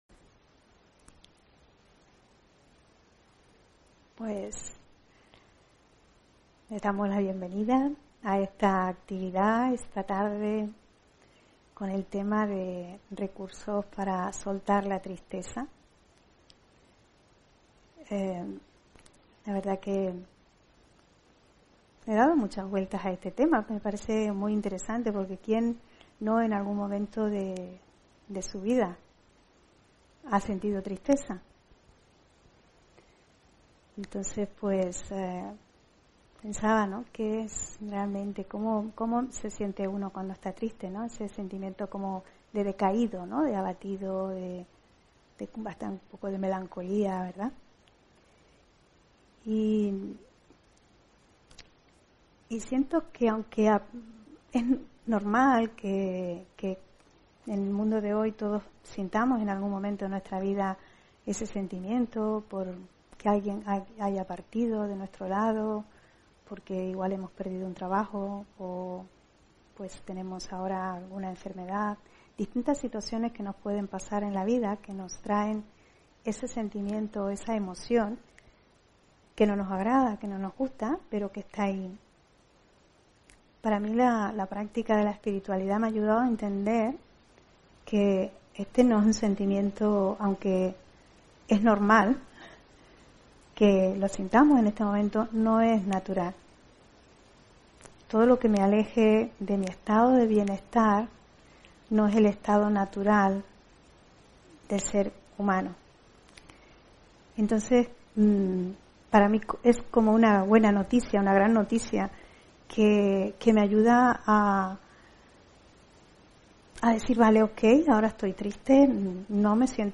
Meditación y conferencia: Recursos para soltar la tristeza (23 Junio 2022)